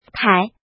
怎么读
tǎi
tai3.mp3